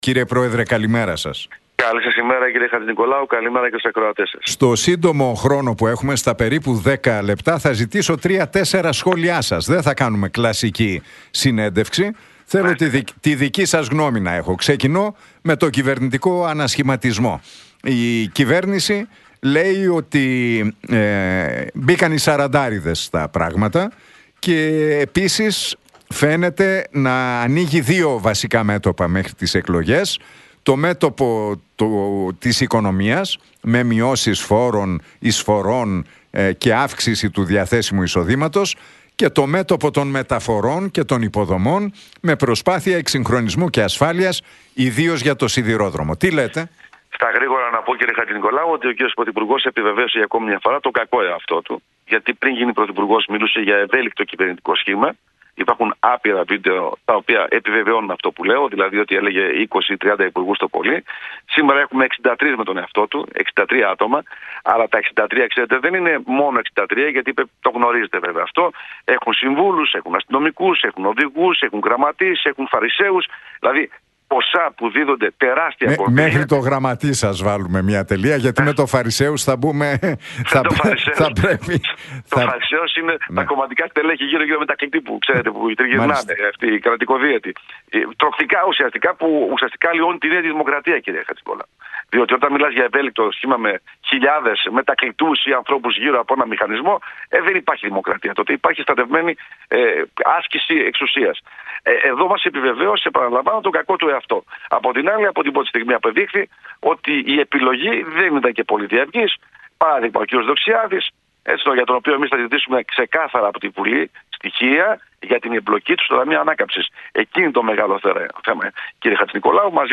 Για τα ελληνοτουρκικά, τον ανασχηματισμό και τις γεωπολιτικές εξελίξεις μίλησε ο πρόεδρος της Ελληνικής Λύσης, Κυριάκος Βελόπουλος στον Νίκο Χατζηνικολάου από την συχνότητα του Realfm 97,8.